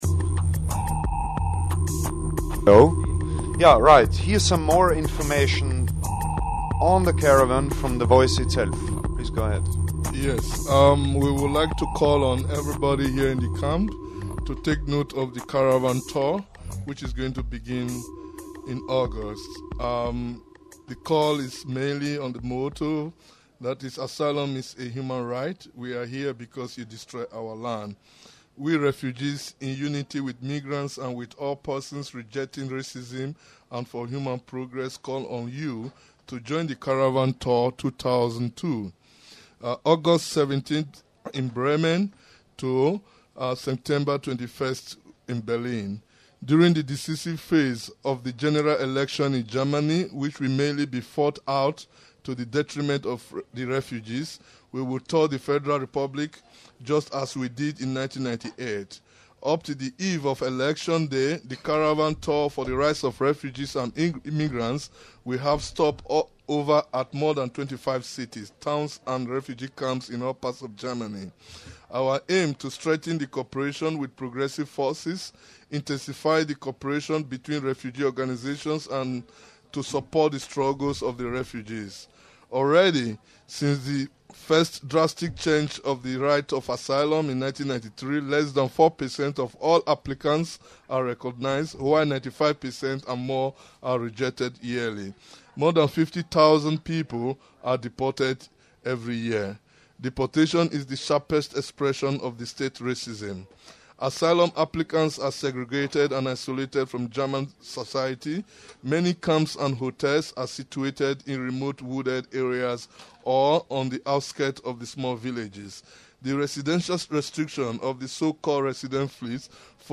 Op het noborderkamp in strasbourg sprak ascii's nieuwsuur met iemand van de Voice, een organisatie voor vluchtelingen, en met de groep die binnenkort het PGA (Peoples Global Action) congres in Leiden organiseert.